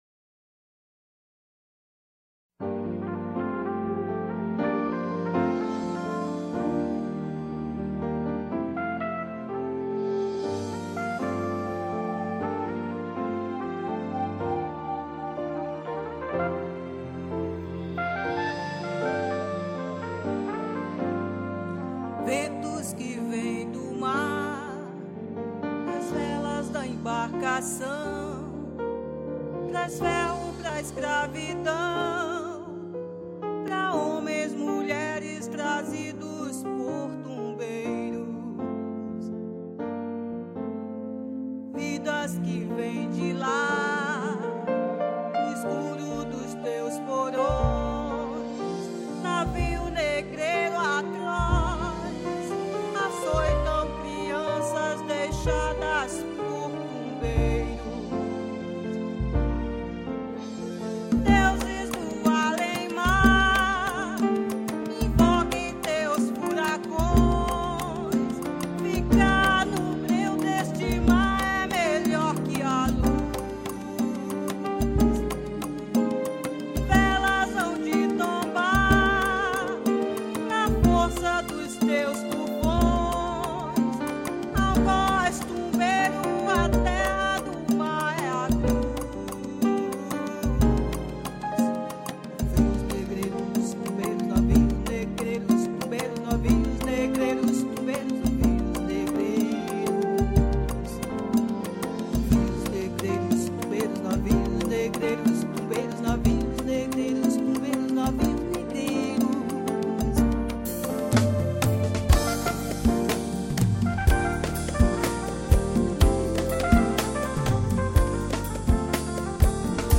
5   04:19:00   Faixa:     Canção Afro
Agogo, Congas
Piano Acústico, Teclados
Bateria
Guitarra
Baixo Elétrico 6